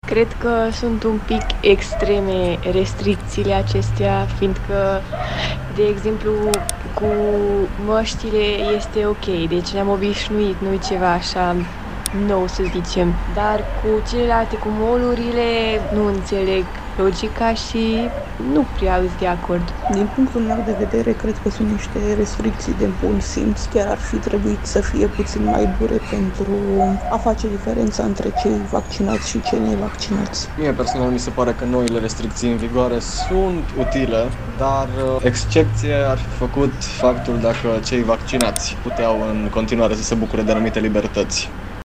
Iată cum văd mureșenii restricțiile valabile de azi în toată țara: